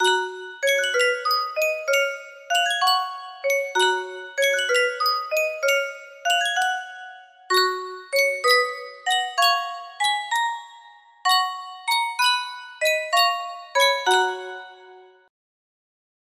Sankyo Spieluhr - O Maria WHQ music box melody
Full range 60